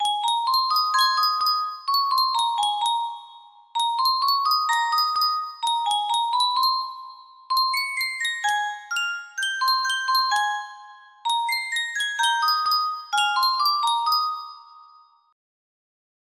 Sankyo Spieluhr - Schwarzwaldmaedel KA music box melody
Full range 60